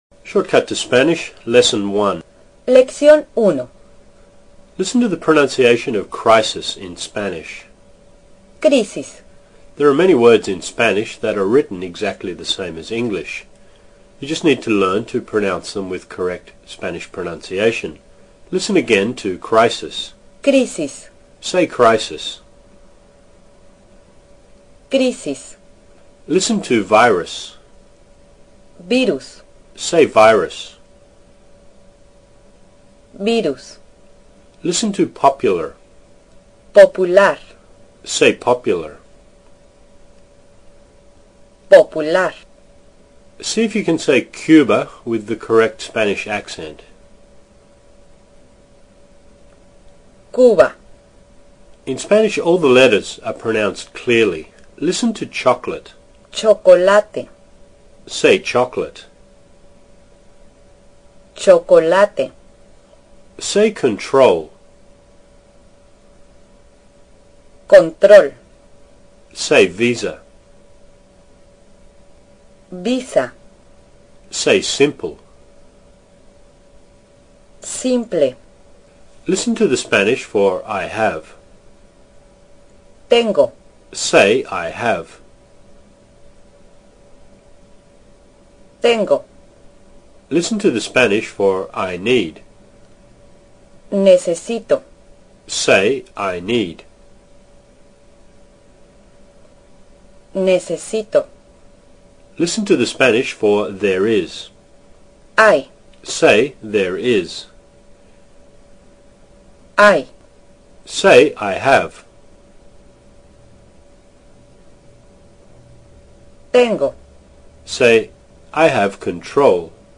Here are 4 MP3 Spanish lessons to get you started.